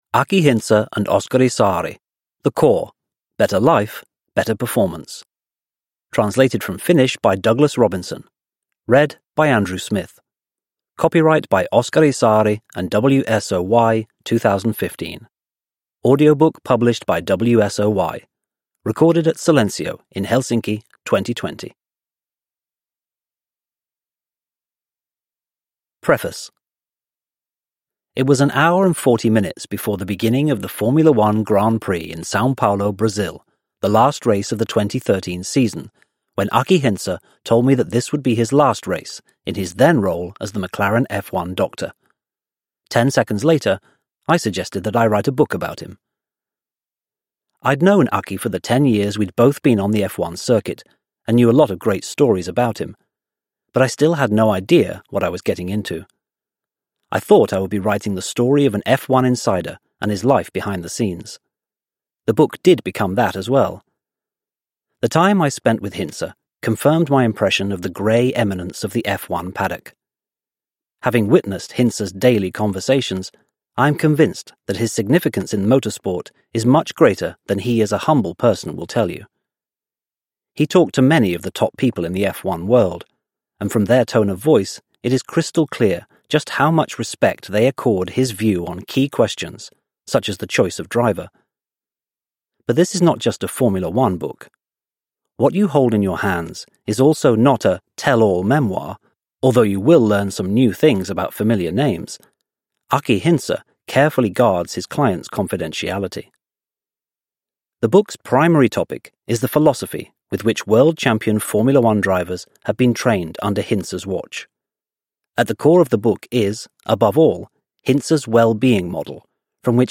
The Core - Better Life, Better Performance – Ljudbok – Laddas ner